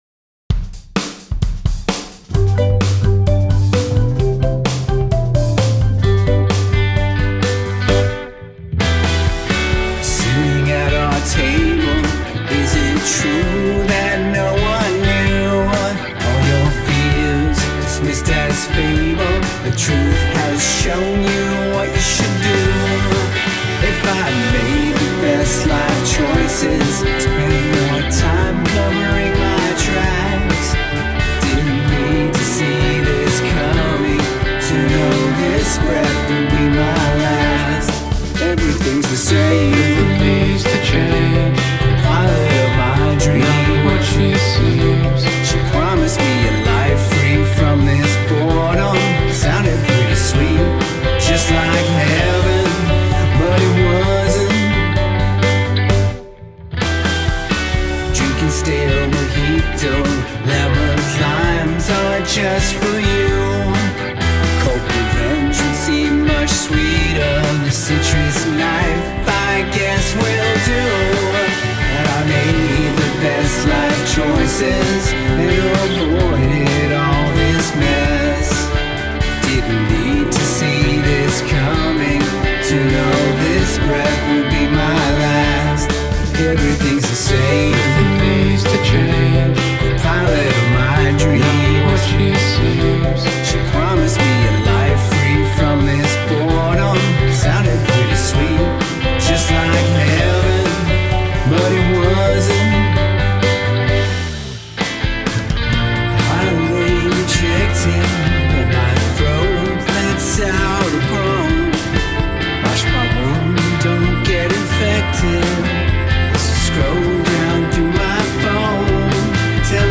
Guest Lead Vocals
This is indie rock, twee gold!
It’s a sweet catchy pop tune.